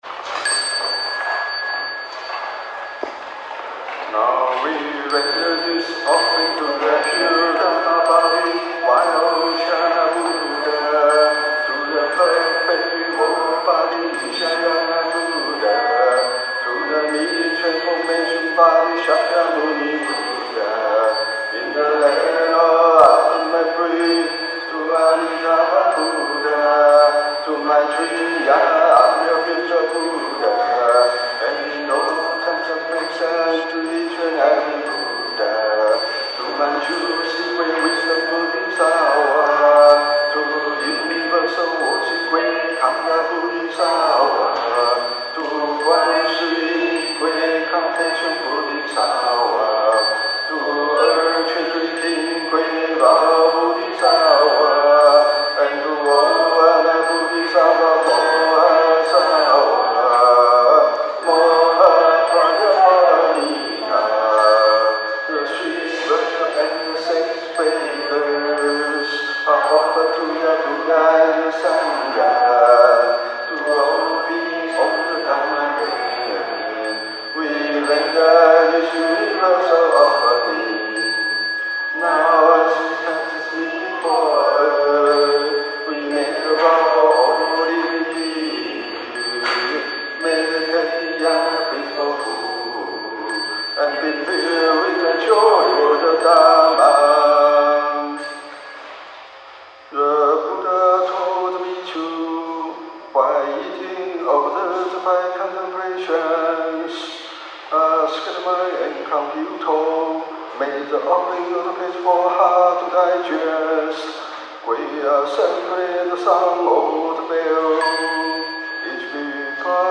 Recitation:
Meal Offering Chant (English) 3.86 MB